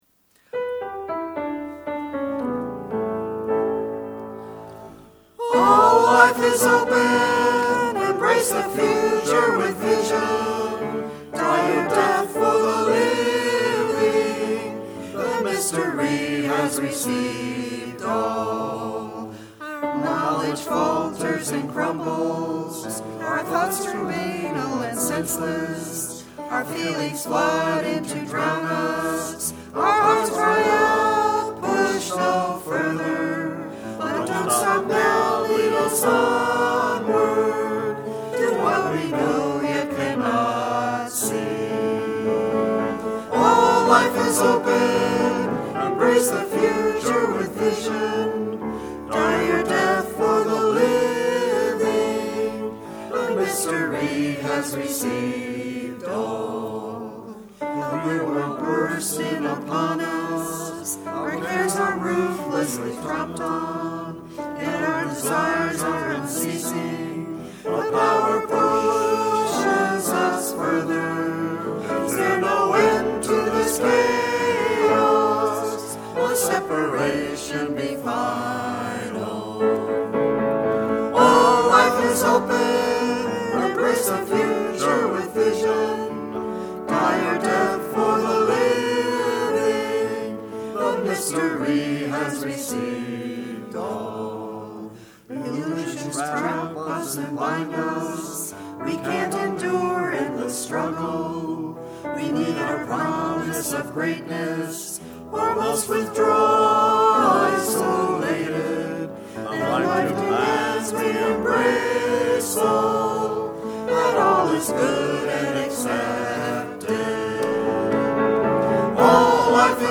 Click on the link to view or download -- * songs.doc 14 Aug 2008 In Troy, NY a group of colleagues and a couple of new folks spent just 2 hours recording some ICA songs.